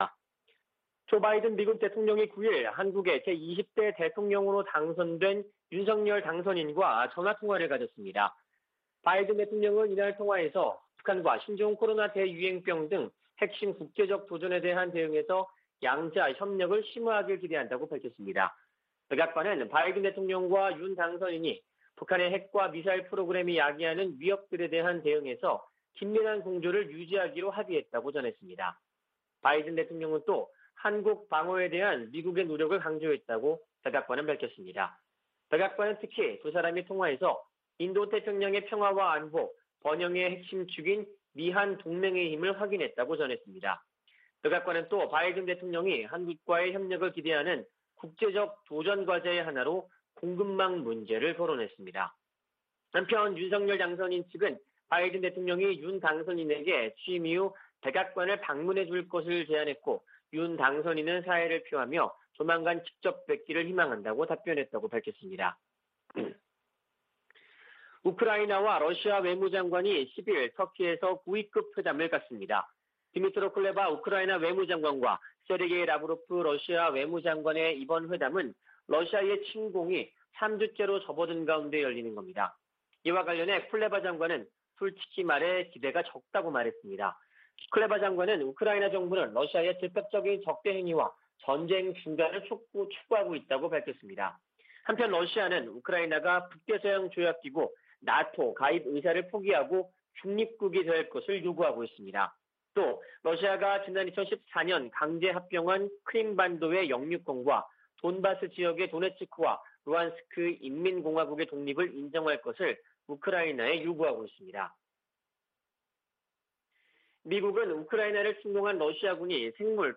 VOA 한국어 '출발 뉴스 쇼', 2022년 3월 11일 방송입니다. 한국 대통령 선거에서 윤석열 후보가 승리했습니다.